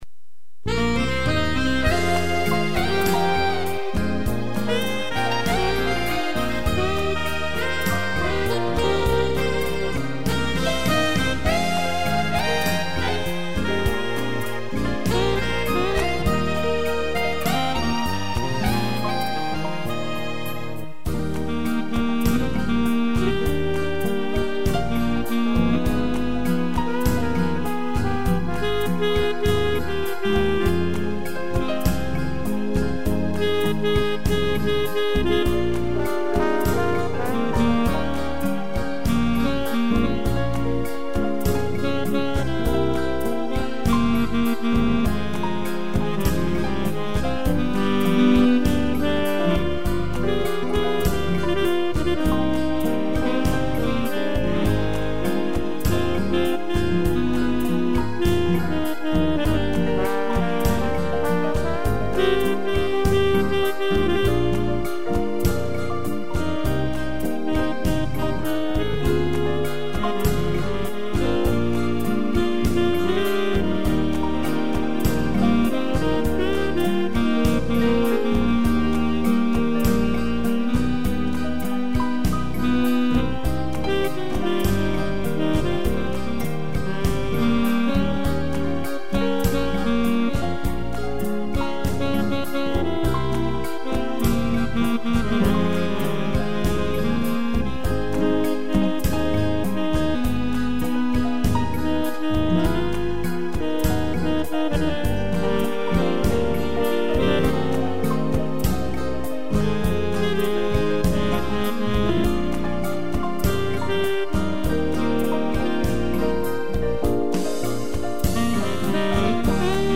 piano, sax e trombone
(instrumental)